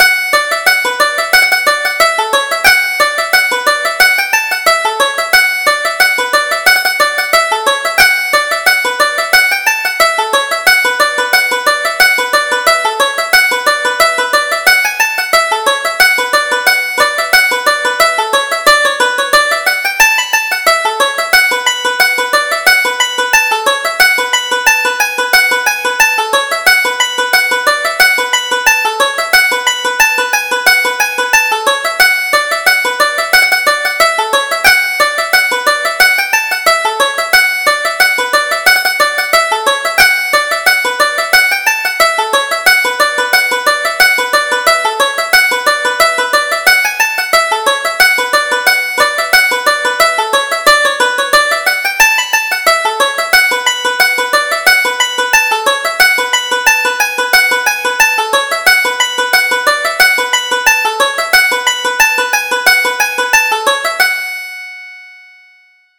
Reel: Sleepy Maggie